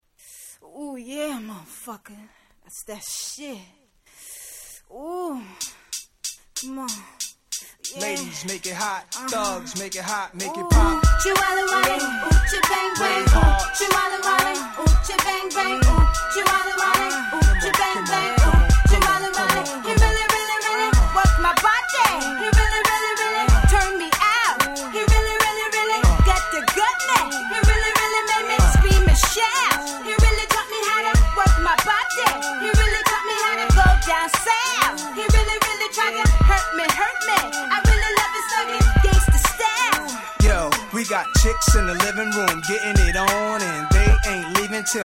00' Super Hit Hip Hop !!